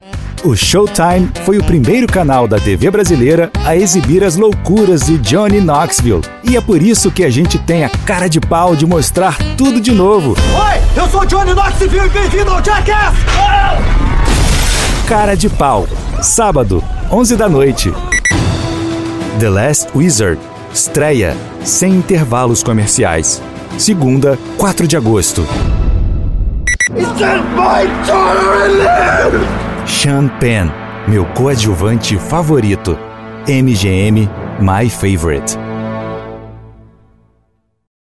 Demonstração Comercial
Macbook M1 2020, AKG K72 e Apogee HypeMic
Jovem adulto
Meia-idade
Baixo
ConfiávelEsquentarConversacionalAmigáveisConfiávelCorporativoDinâmicoJovemAlegre